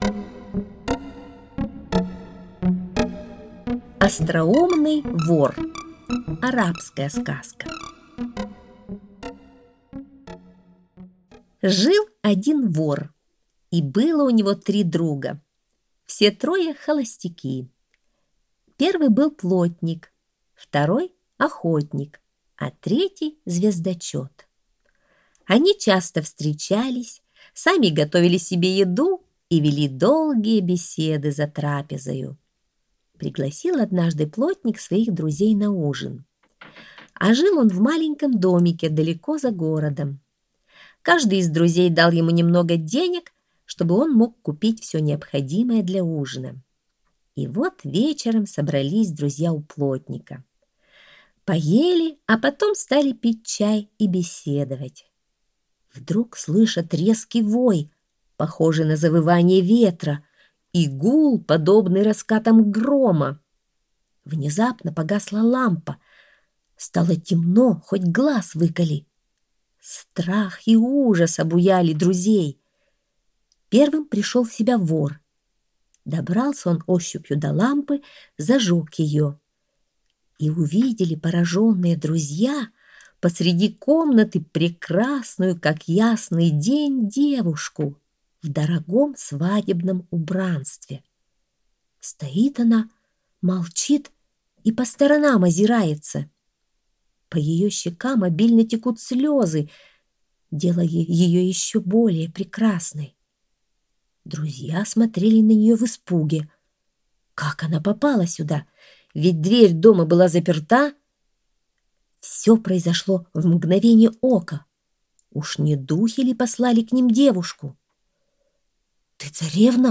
Остроумный вор - арабская аудиосказка - слушать онлайн